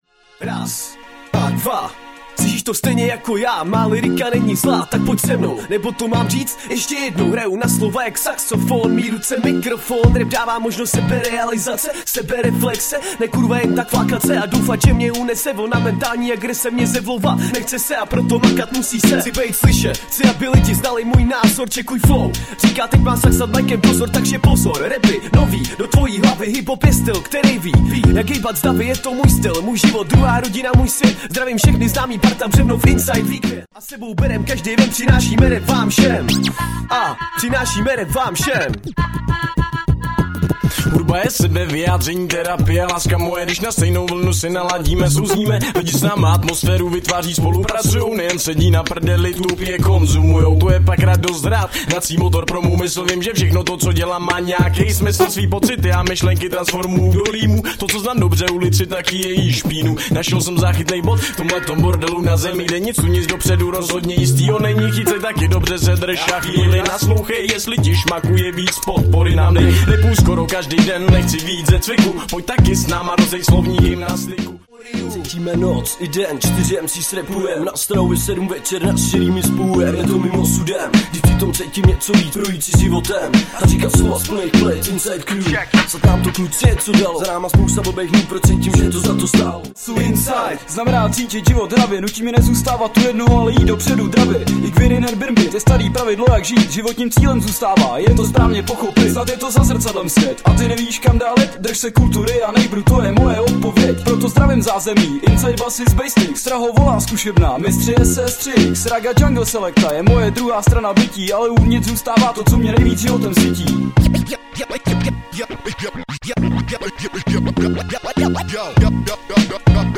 Styl: Hip-Hop